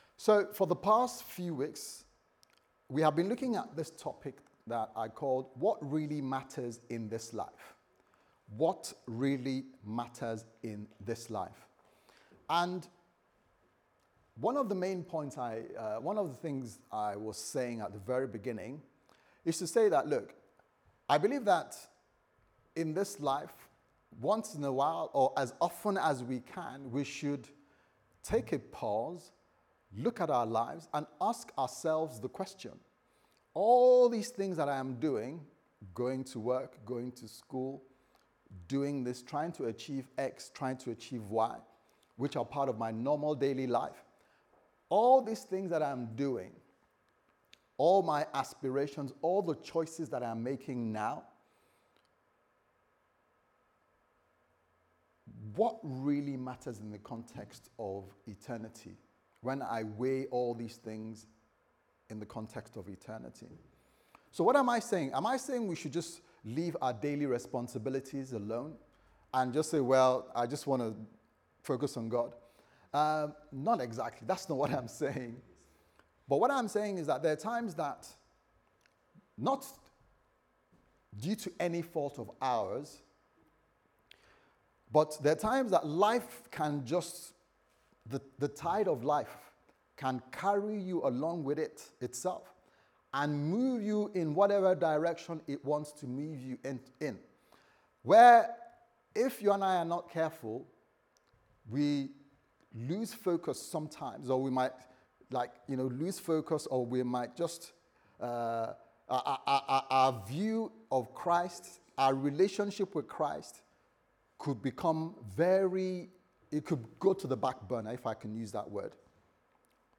What Really Matters Service Type: Sunday Service Sermon « What Really Matters In This Life ?